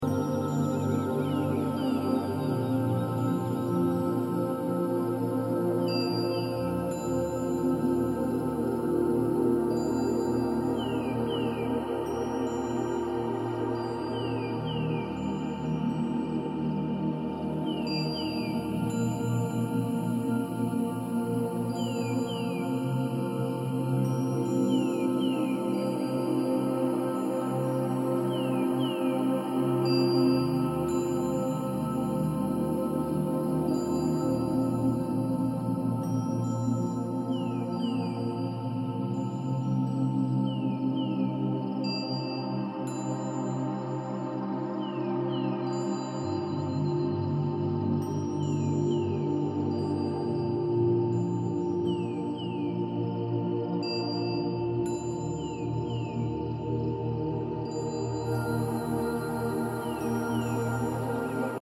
Step into a higher timeline of abundance and soul activation with this 888Hz healing frequency, infused with Sirian starseed light codes.
The 888Hz frequency resonates with infinite flow, financial freedom, and universal balance—perfect for manifestation and inner expansion.